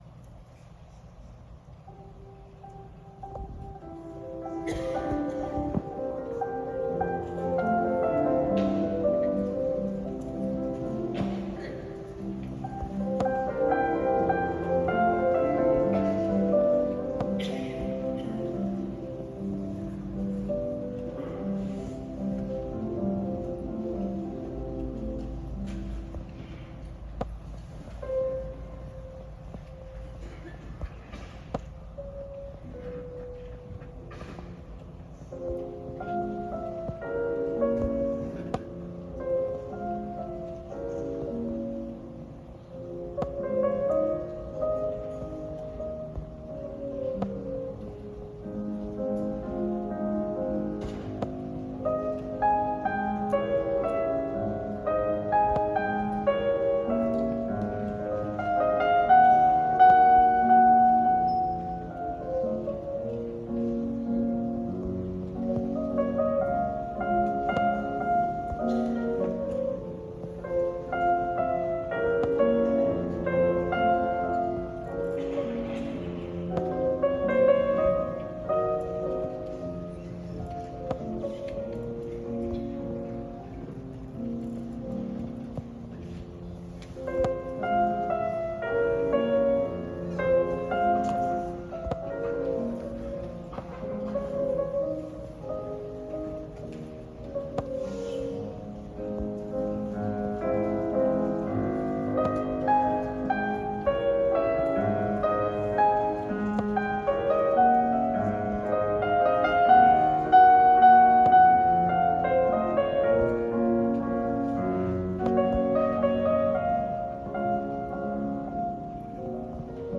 San Francisco Davies Symphony Hall